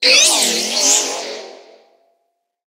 Cri de Méga-Raichu Y dans Pokémon HOME.